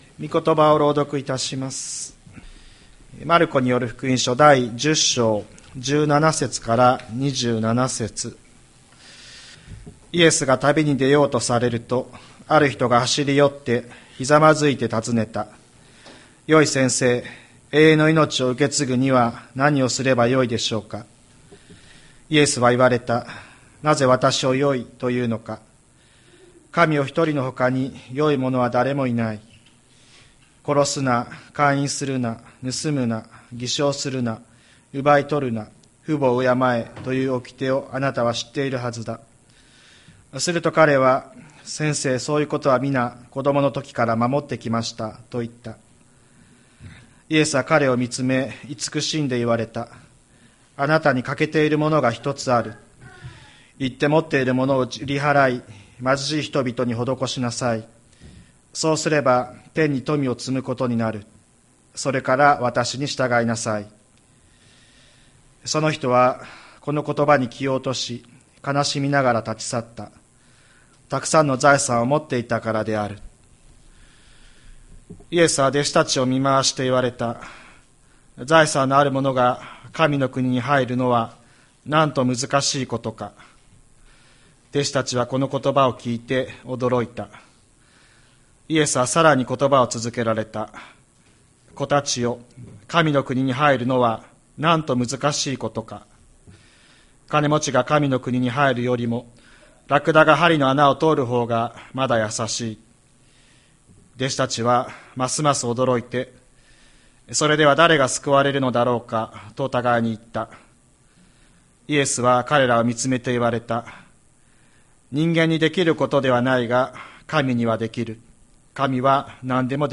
2024年01月21日朝の礼拝「全能の父なる神」吹田市千里山のキリスト教会
千里山教会 2024年01月21日の礼拝メッセージ。